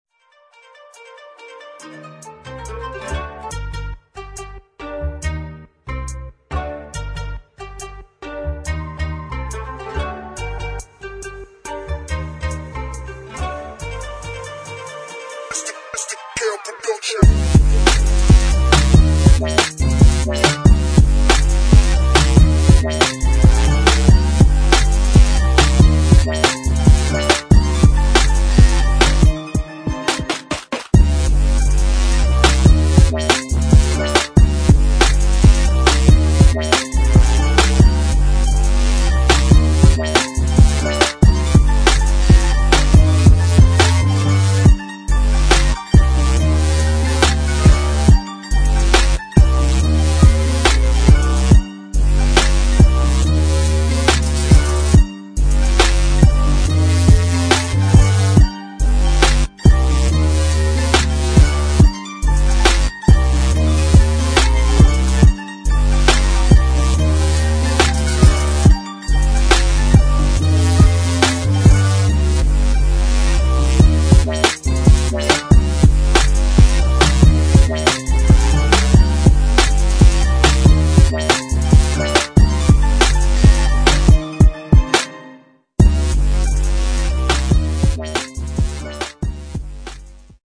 [ GRIME / DUBSTEP ]